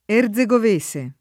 vai all'elenco alfabetico delle voci ingrandisci il carattere 100% rimpicciolisci il carattere stampa invia tramite posta elettronica codividi su Facebook erzegovese [ er Z e g ov %S e o er z e g ov %S e ] etn. (dell’Erzegovina)